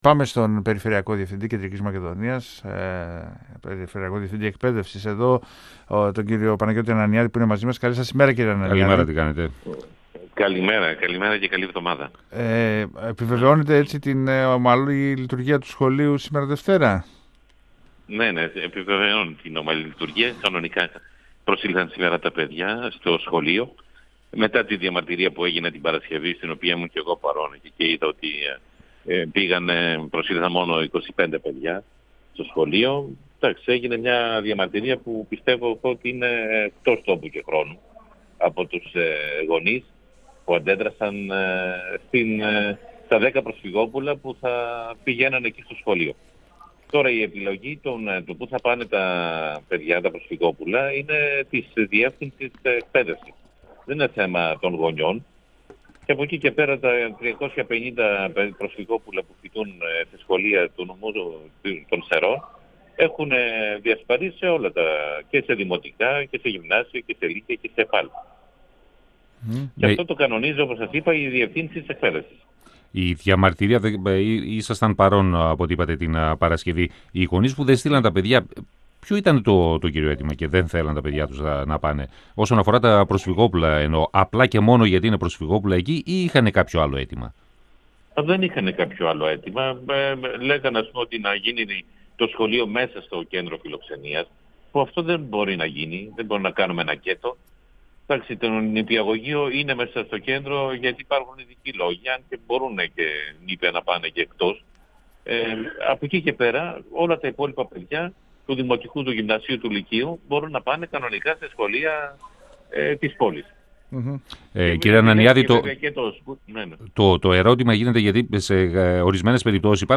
O Περιφερειακός Διευθυντής Α’ βάθμιας και Β’ βάθμιας Εκπαίδευσης Κεντρικής Μακεδονίας, Παναγιώτης Ανανιάδης, στον 102FM του Ρ.Σ.Μ. της ΕΡΤ3